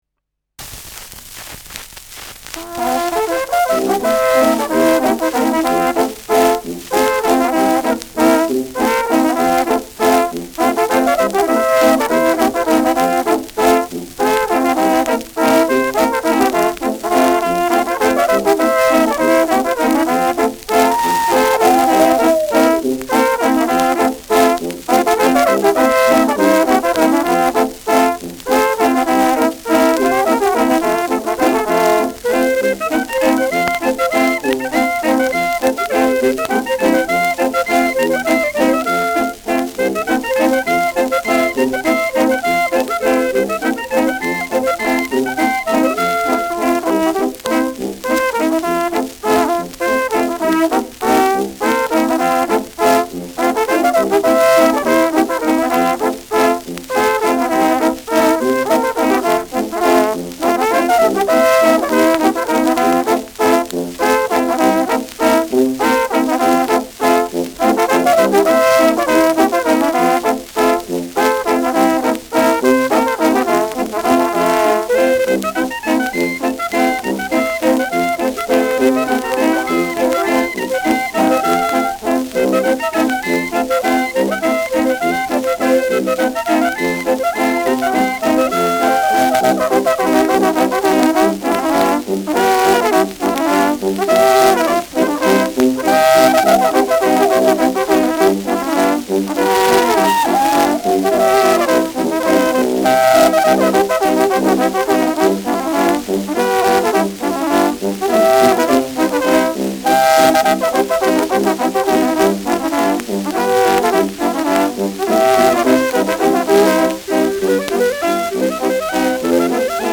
Schellackplatte
Tonrille: graue Rillen : leichte Kratzer durchgängig
präsentes Rauschen : präsentes Knistern : leichtes „Schnarren“ : gelegentliches Knacken : leichtes Leiern